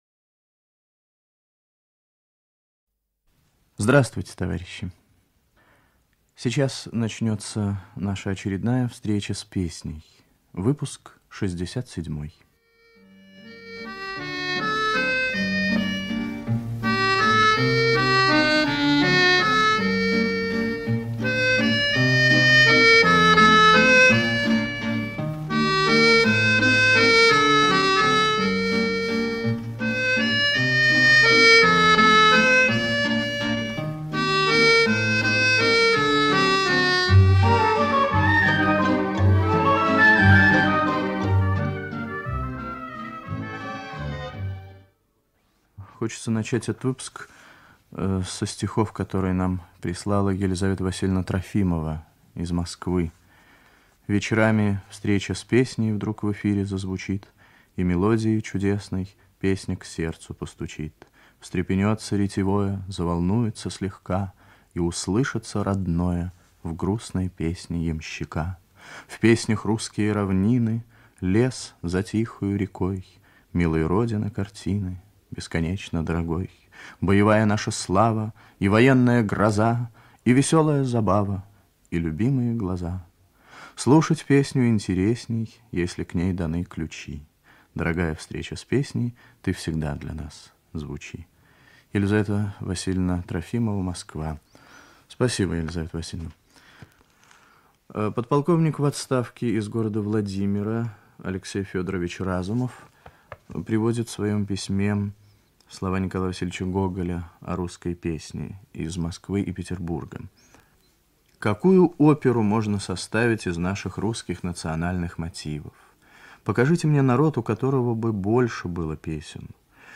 Радиопередача "Встреча с песней" Выпуск 67
Ведущий - автор, Виктор Татарский